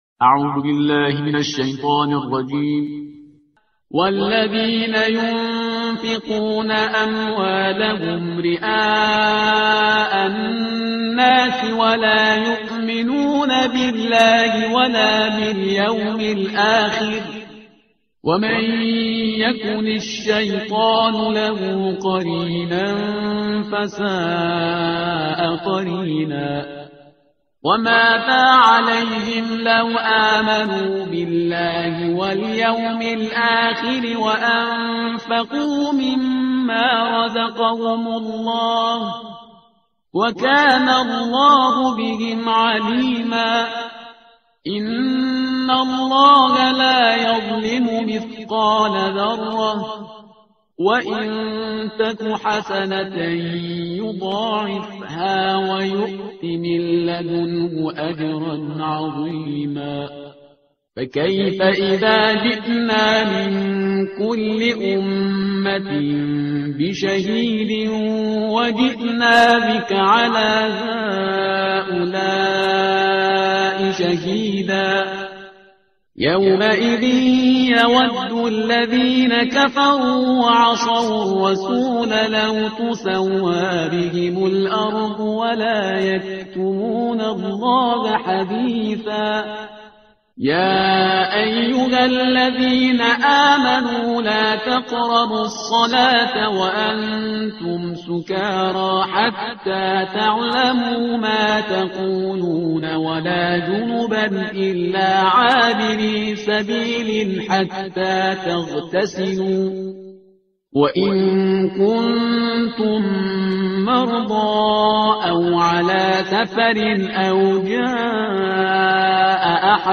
ترتیل صفحه 85 قرآن – جزء پنجم